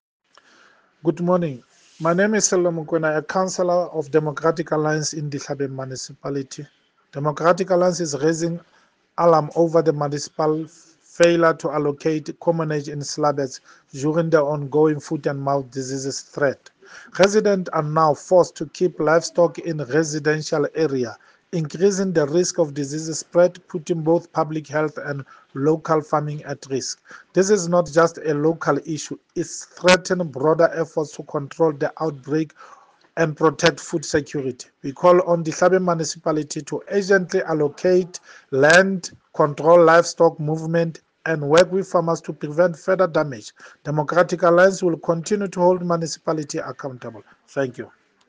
Sesotho soundbites by Cllr Sello Makoena and